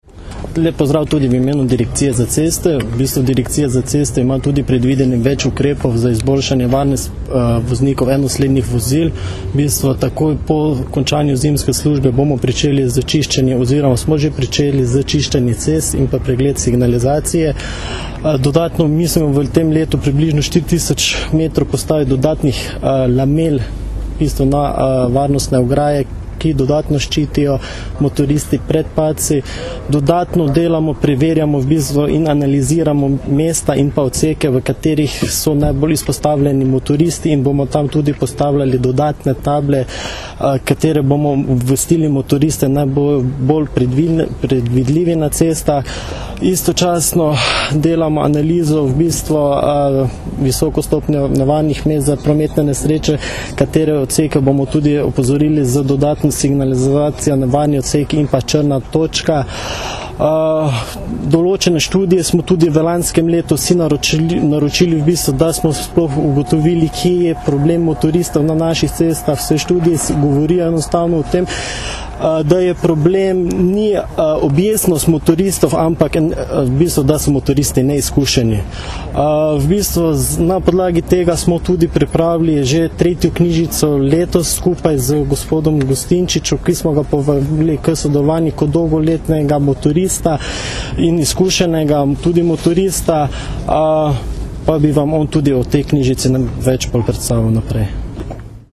Ob začetku motoristične sezone predstavili akcijo za večjo varnost motoristov - informacija z novinarske konference
izjava (mp3)